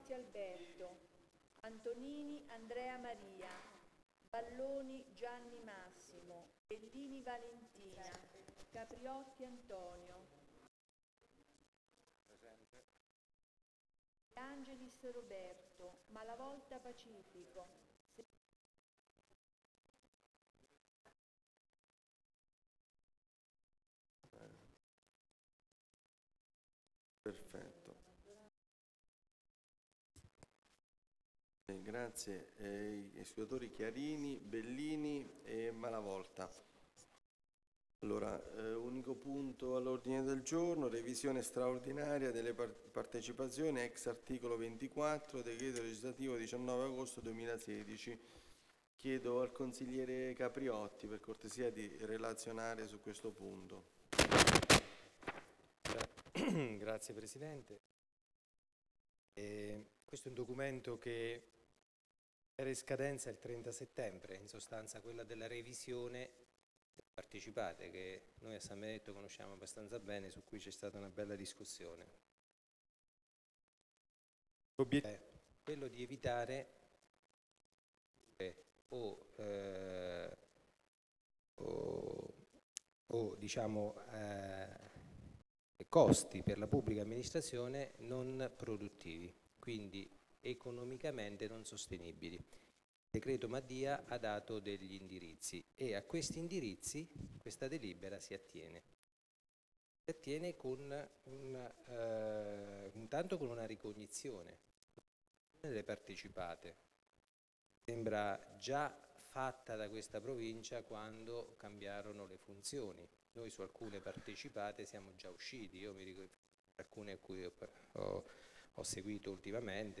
Seduta del consiglio del 30 Ottobre 2017